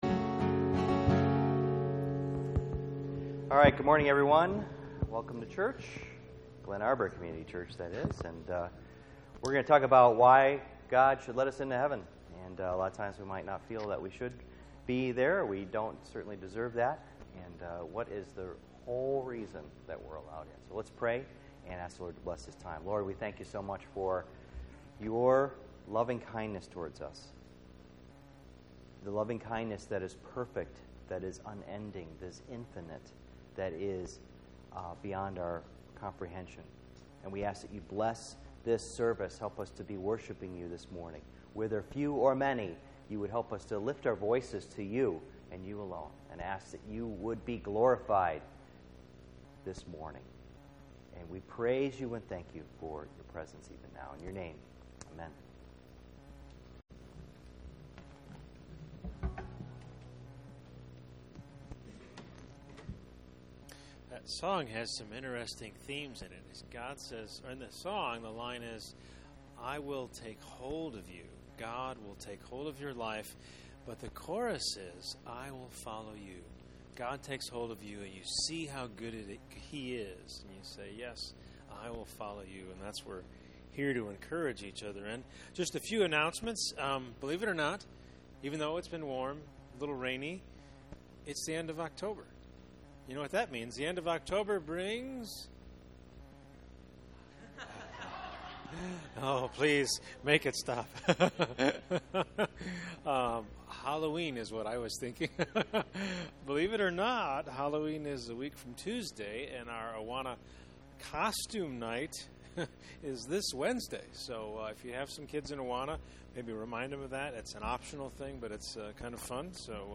Heaven and Hell Service Type: Sunday Morning %todo_render% « Does God send people to hell?